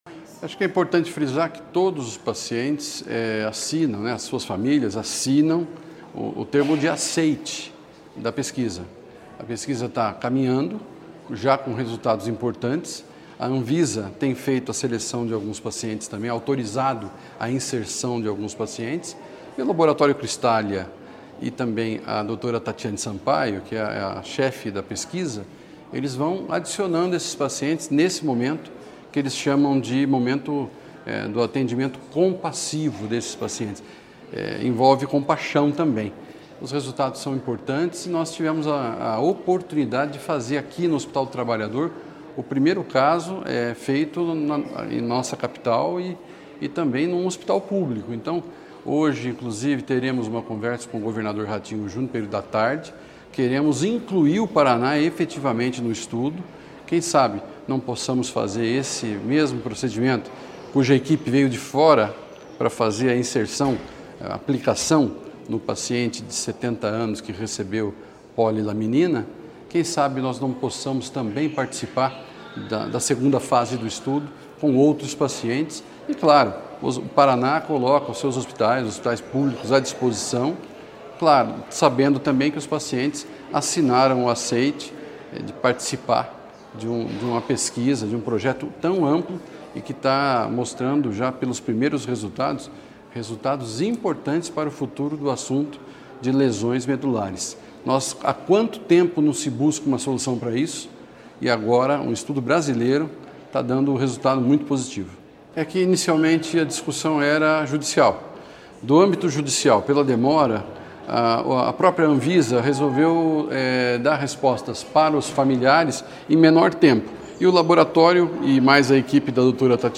Sonora do secretário da Saúde, Beto Preto, sobre as aplicações de polilaminina no Paraná